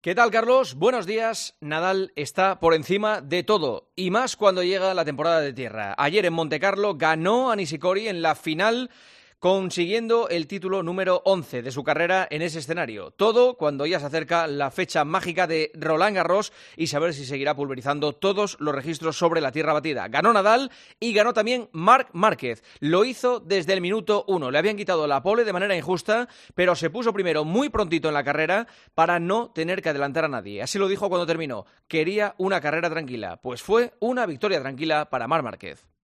El comentario de Juanma Castaño
Escucha el análisis de la actualidad deportiva en voz del director de 'El Partidazo' de COPE